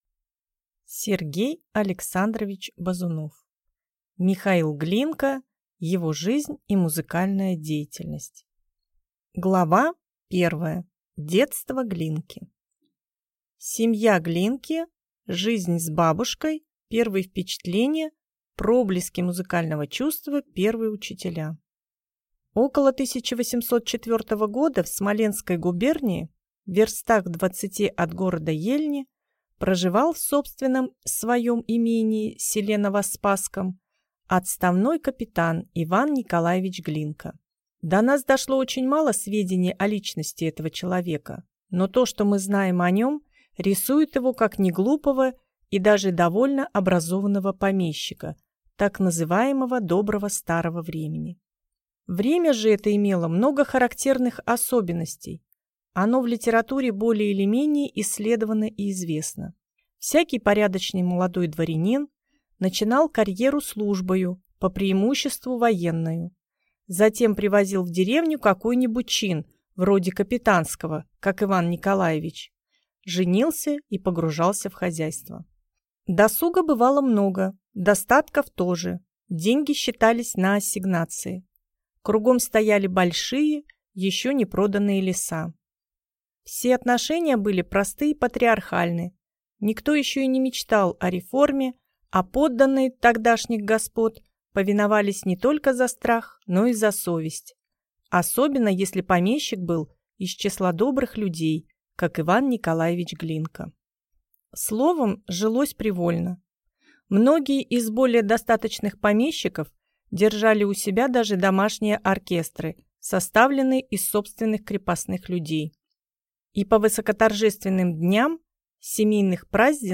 Аудиокнига Михаил Глинка. Его жизнь и музыкальная деятельность | Библиотека аудиокниг